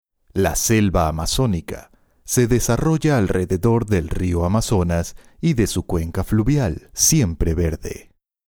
spanisch Südamerika
Sprechprobe: Industrie (Muttersprache):